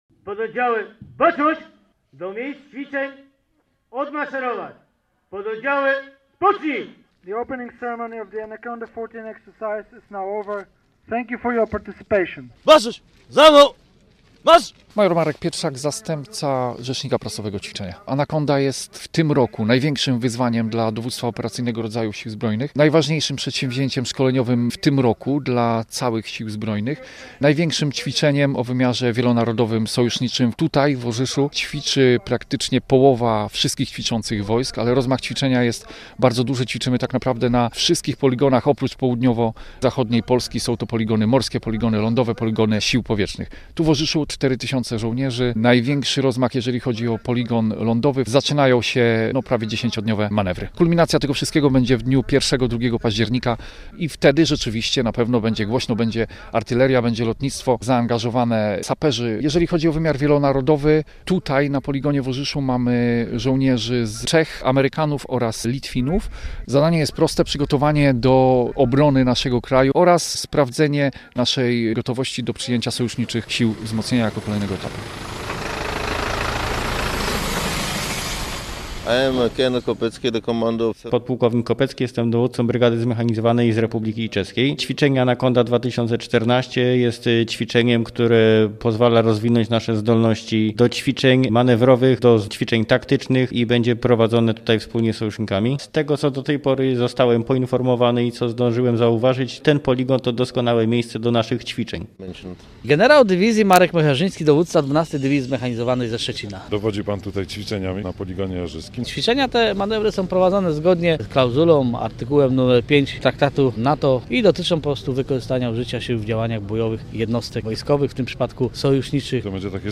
Ćwiczenia wojskowe "Anakonda2014" na poligonie w Orzyszu